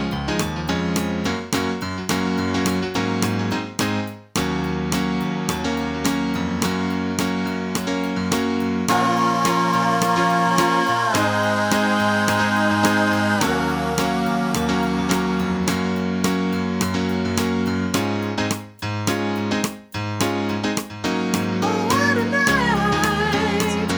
No Drums Bass Or Main Guitar Pop (1970s) 3:44 Buy £1.50